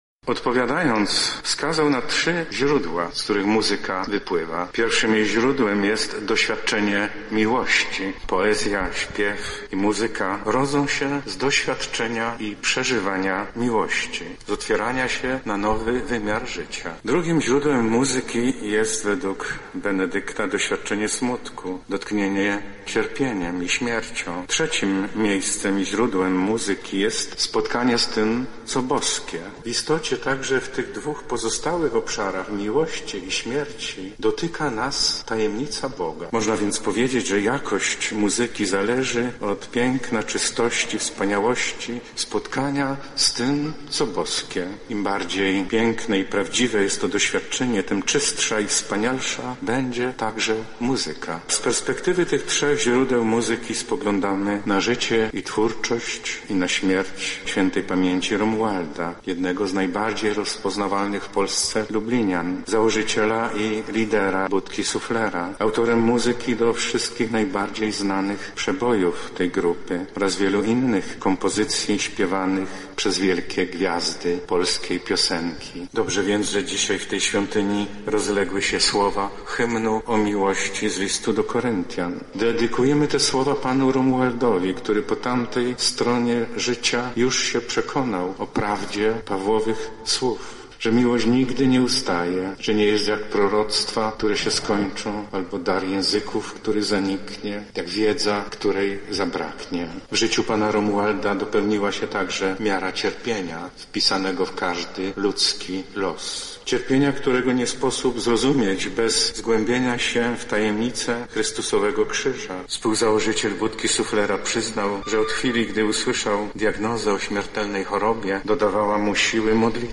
Uroczystości pogrzebowe artysty rozpoczęła msza w archikatedrze lubelskiej sprawowana przez arcybiskupa Stanisława Budzika.
Kazanie